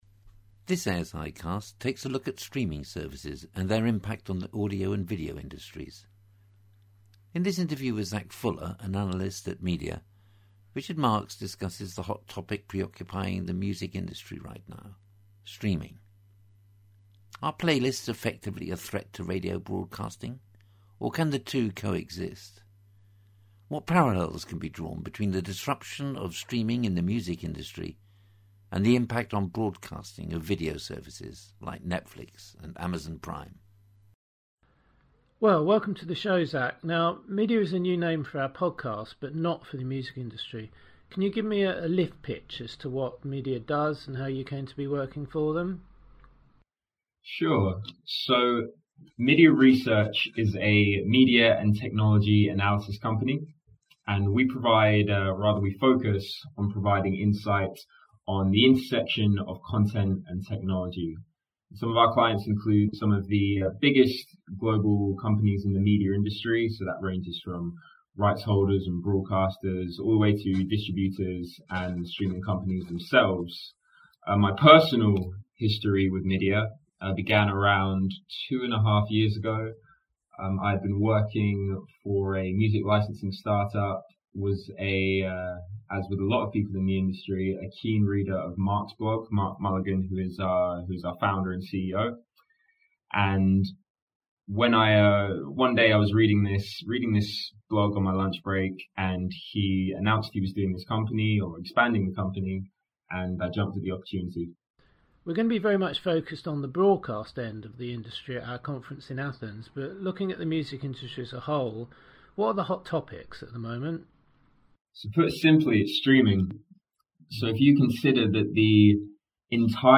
Do such playlists compete with radio or do they complement it? The interview also explores the similarities and differences driving the development of streaming services like Spotify in audio and Netflix in video.